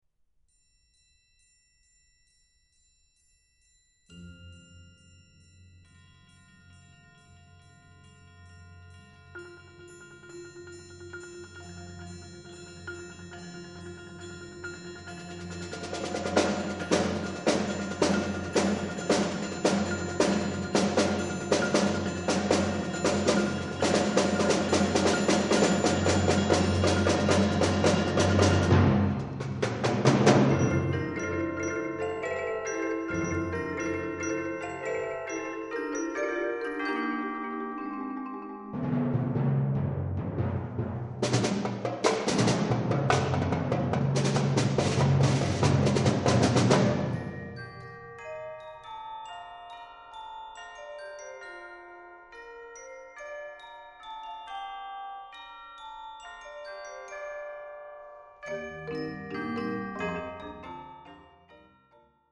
Scored for twelve performers.